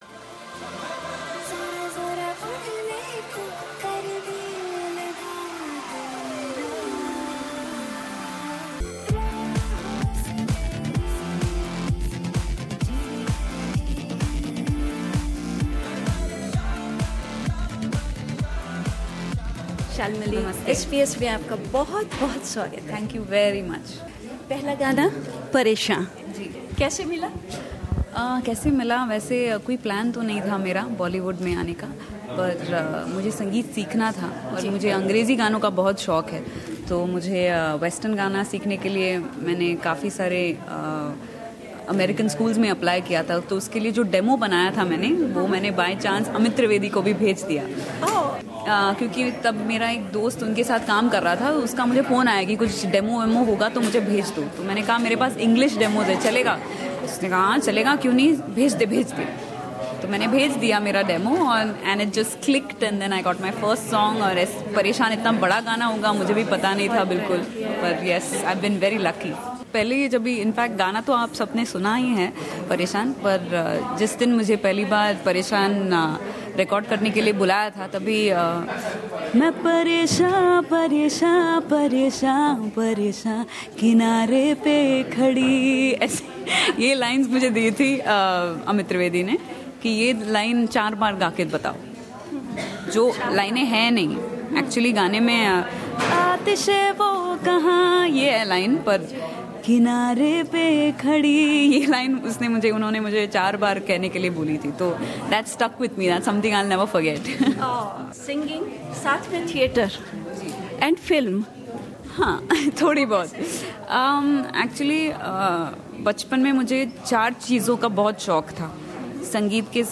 बातचीत में गायिका शालमली खोलगड़े कहती हैं।